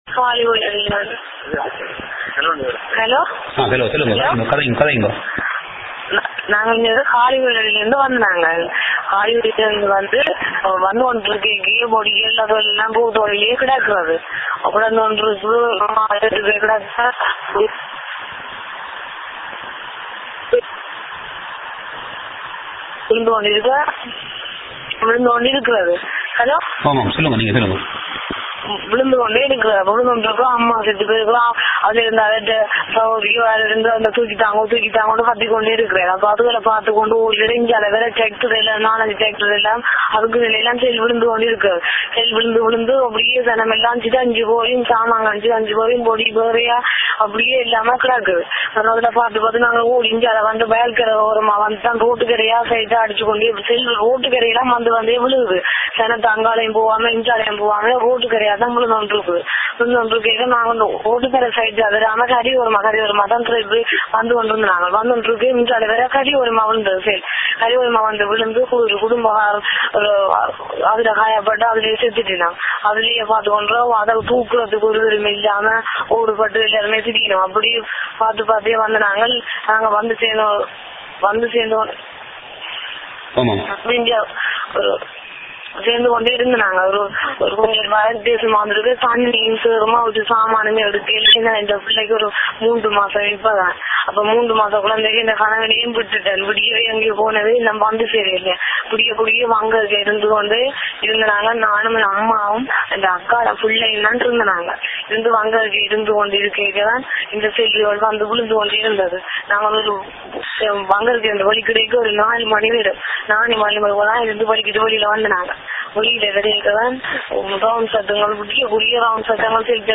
[EYEWITNESS ACCOUNT: AUDIO]
TamilNet publishes direct eyewitness accounts from the street of Udaiyaarkaddu.